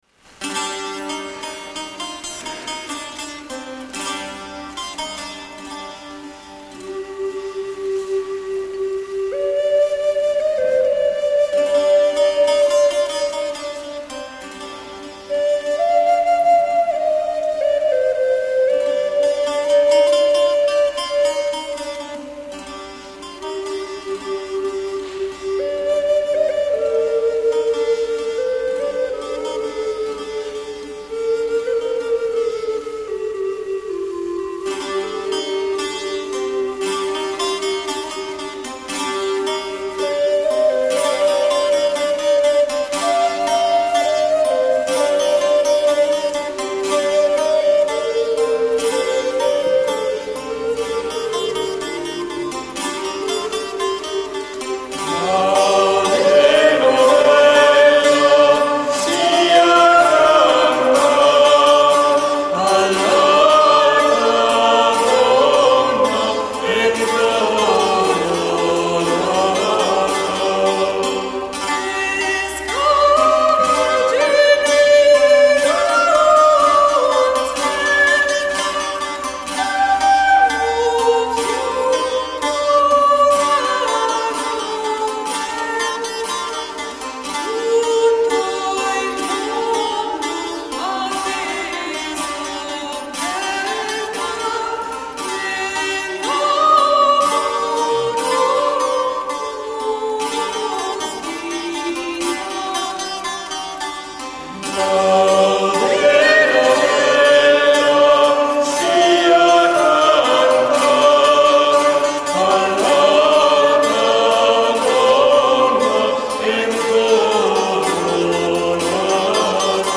che si dedica alla ricerca nel campo delle musiche medioevali, rinascimentali e barocche, usando ricostruzioni di strumenti dell’epoca.